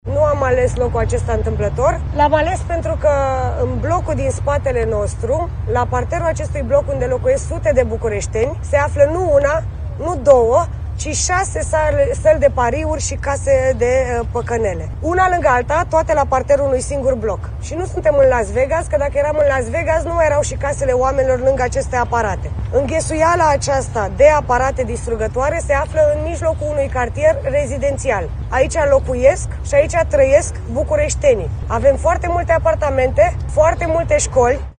Deputata USR Diana Stoica, aflată alături de colegi în zona Gorjului-Militari: „Nu suntem în Las Vegas, pentru că, dacă eram în Las Vegas, nu mai erau casele oamenilor lângă aceste aparate”
Mai mulți membri USR, într-o conferință de presă ținută într-un cartier de blocuri, au ținut în mâini pancarde cu mesajele: „Fără păcănele lângă școli” și „Jocurile de noroc distrug vieți”.